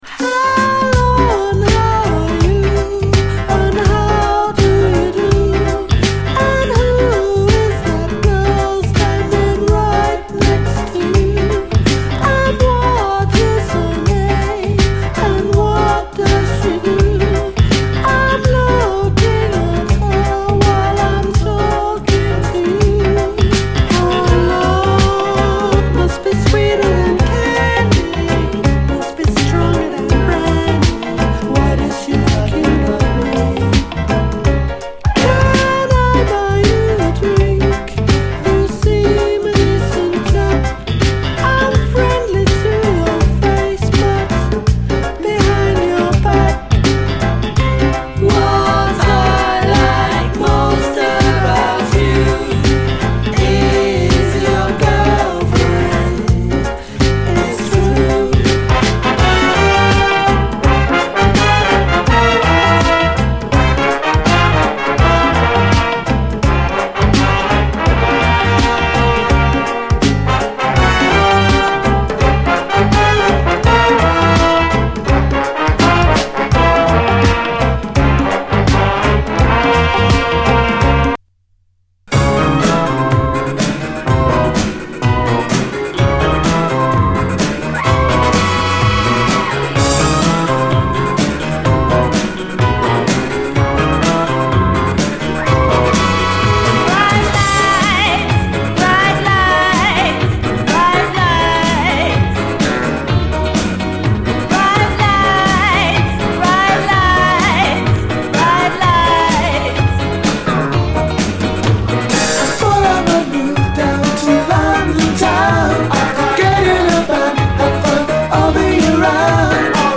ファットなビートに加え女性コーラスのアレンジも素晴らしい
泣きの哀愁メロウ・ファンク
後半にドラム・ブレイクもあり。